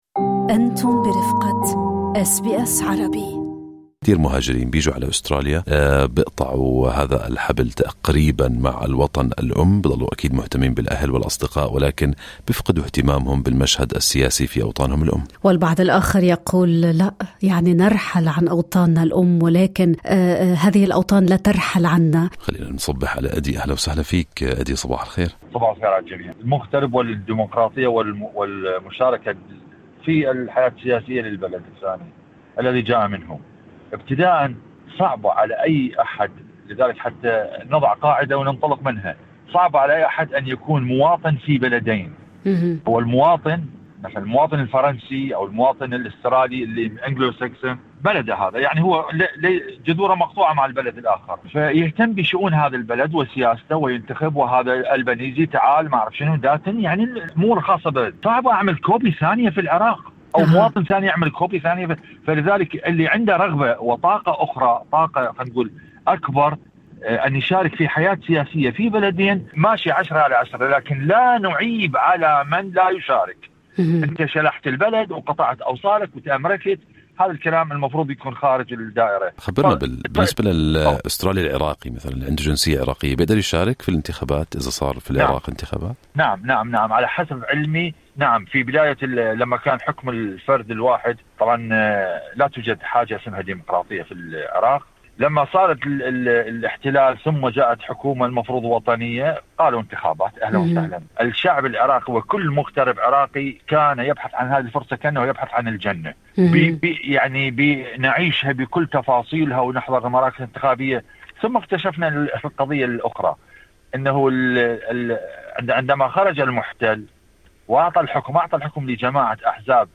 هذه العلاقة المستمرة مع الأوطان الأصلية تتجلى بوضوح خلال الاستحقاقات الانتخابية. هل يكترث الأسترالي من أصول عربية بالانتخابات في بلده؟ هذا ما سألناه لمستمعينا في فقرة الحوار المباشر في Good Morning Australia